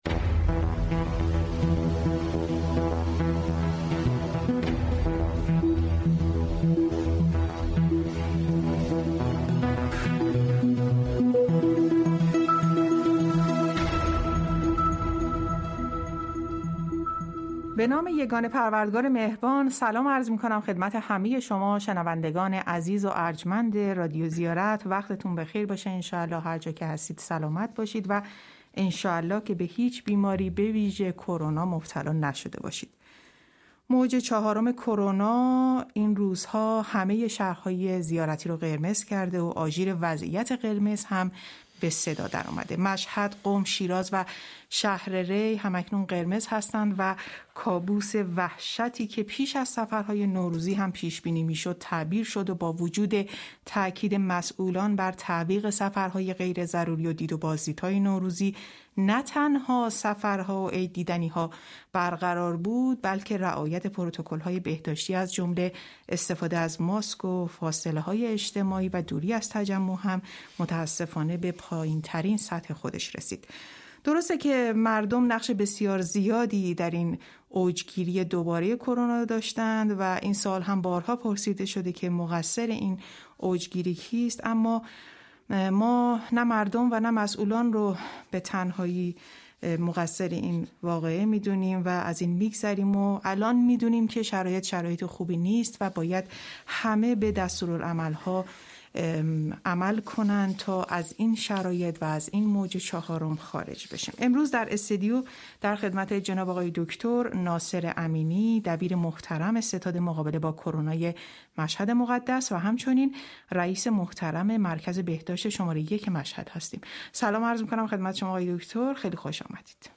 یونس عالی پور معاون هماهنگی امور زائران استانداری قم هم در گفتگوی ویژه خبری رادیو زیارت افزود: برای برنامه های ماه رمضان منتظر تصمیم ستاد مقابله با کرونا هستیم اما همه برنامه ها و تجملات حرم حضرت معصومه (س) و مسجد جمکران تعطیل شده است .